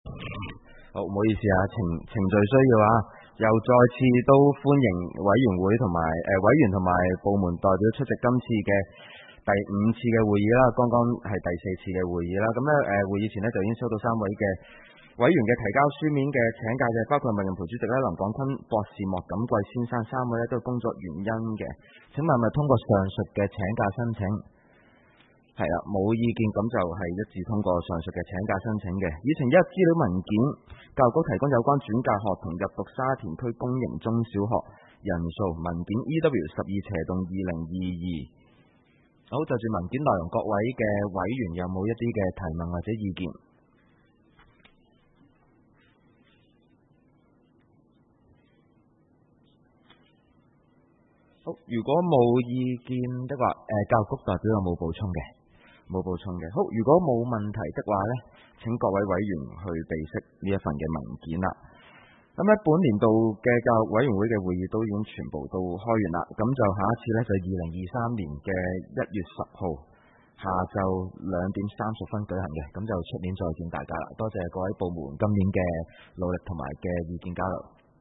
委员会会议的录音记录
地点: 沙田民政事务处441会议室